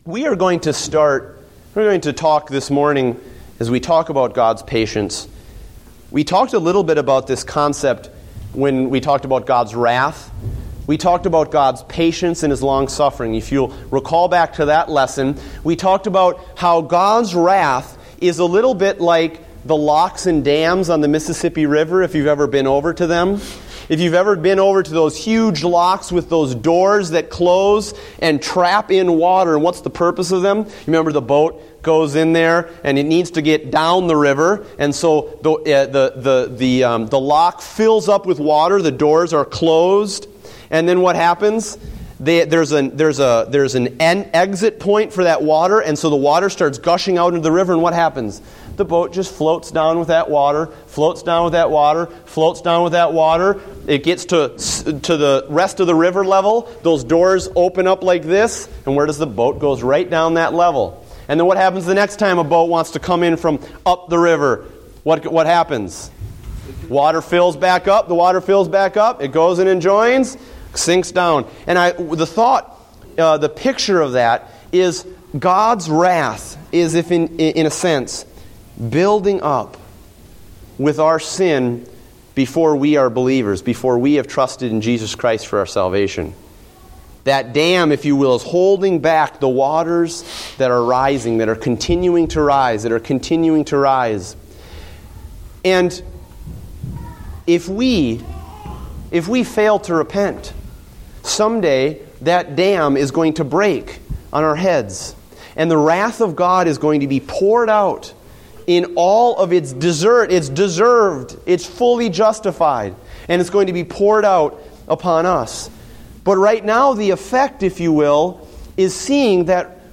Date: September 7, 2014 (Adult Sunday School)